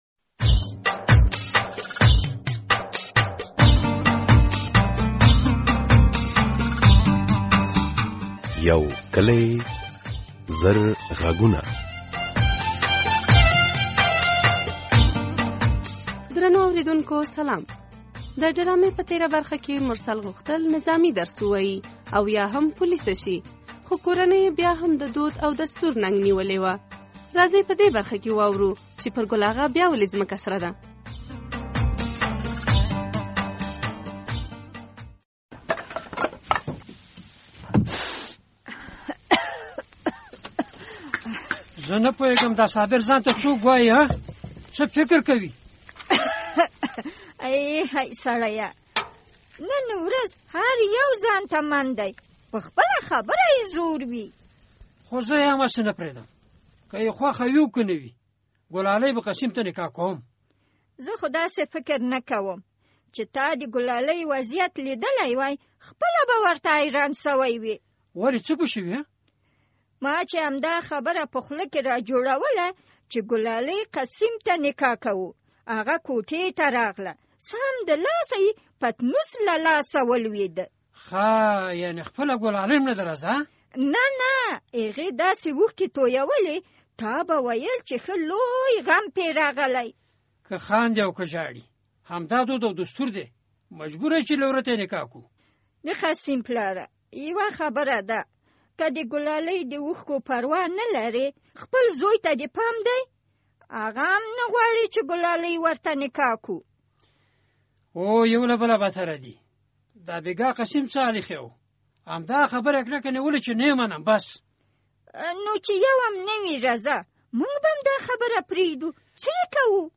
د یو کلي زرغږونو ډرامې په دې برخه کې د نکاح په شرایطو او ګڼو نورو موضوعاتو خبرې شوي دي.